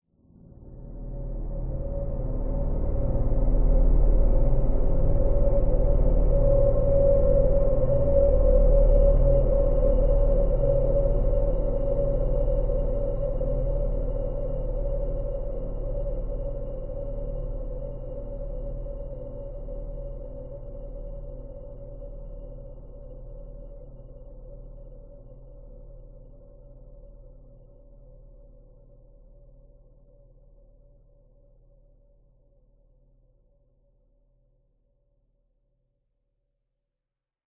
Ужасный нарастающий гул, долгий, мрачный и жалобный, пугающий и зловещий